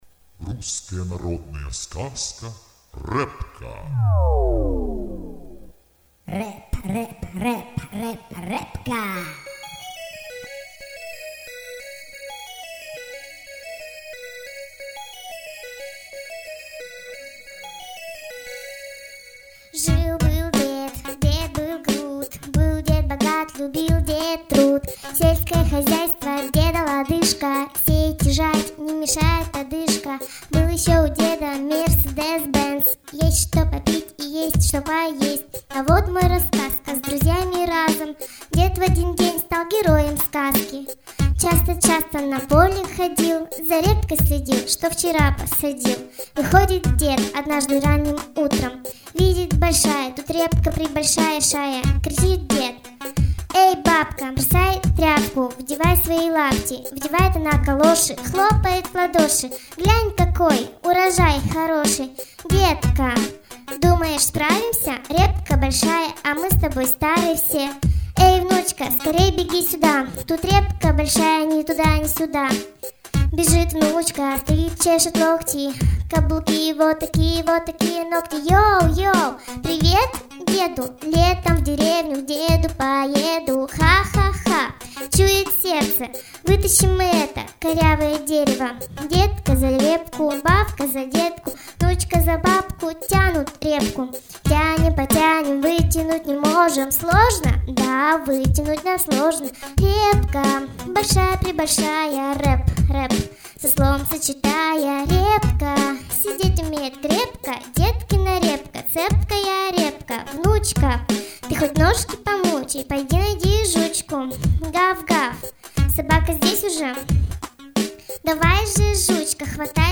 Репка - рэп